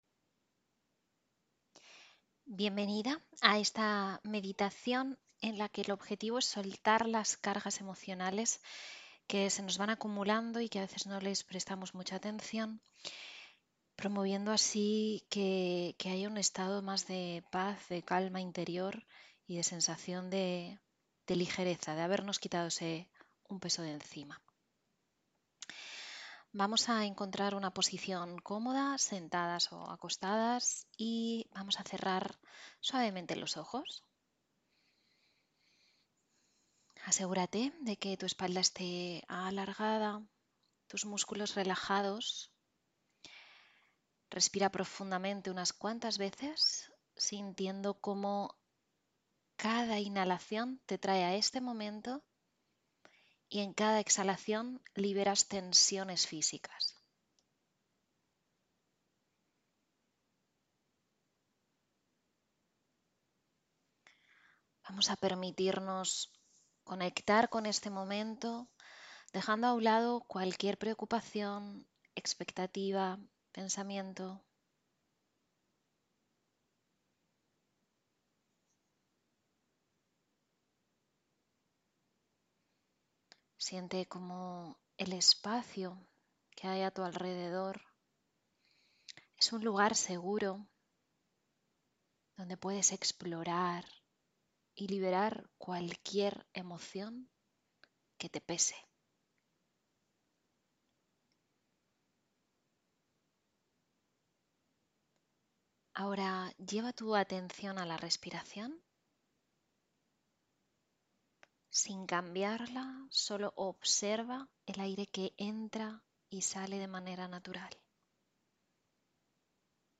Sesión 8: Meditación de Liberación Emocional